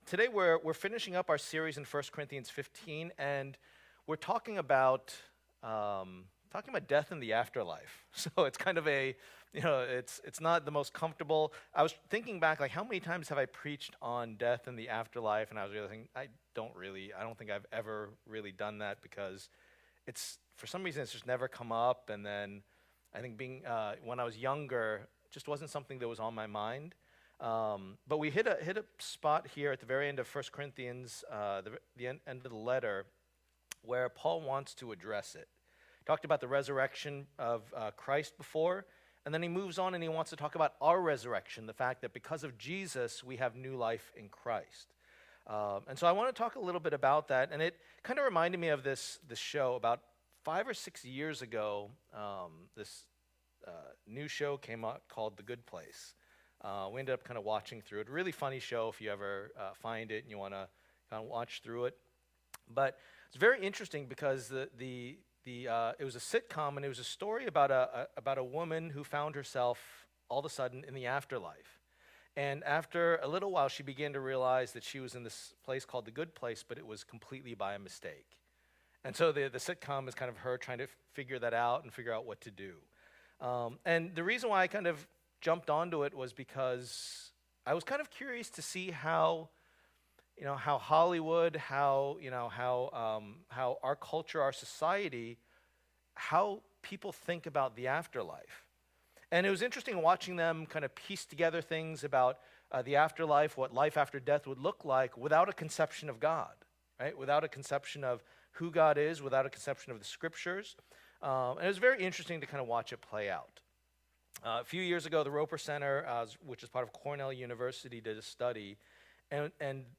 Passage: 1 Corinthians 15:50-58 Service Type: Lord's Day